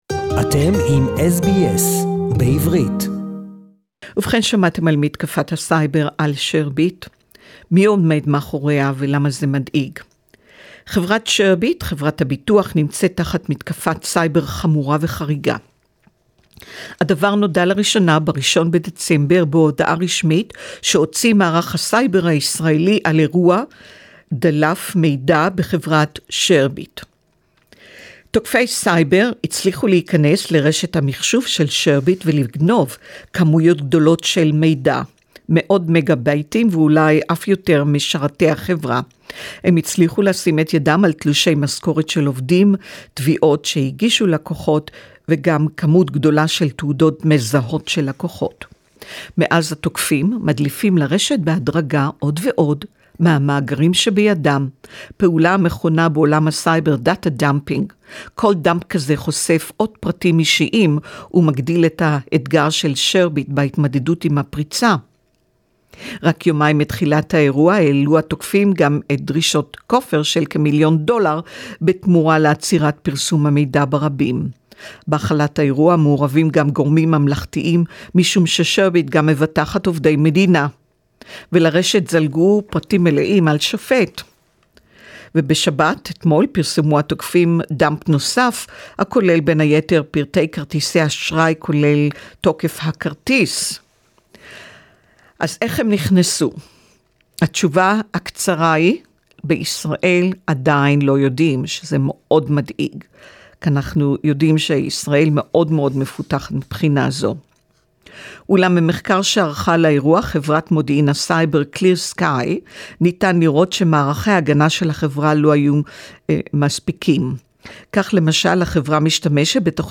(Report in Hebrew)